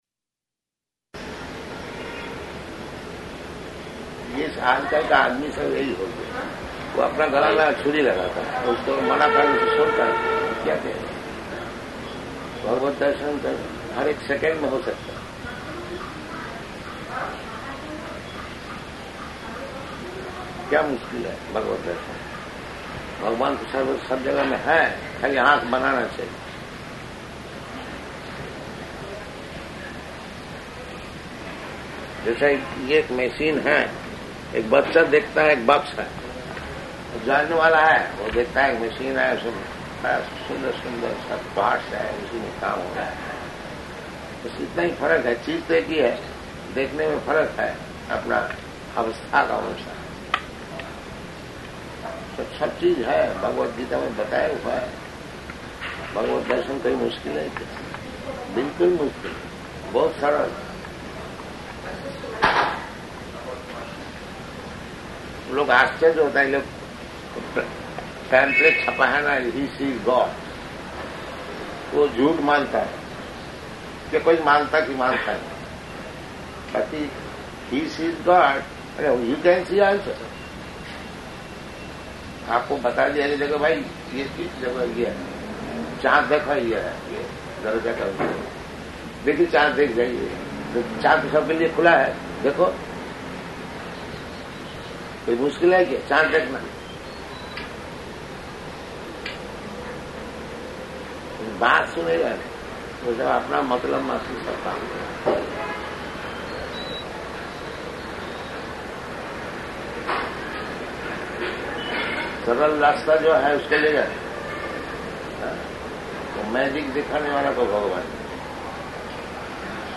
Conversation--Hindi
Type: Conversation
Location: Jakarta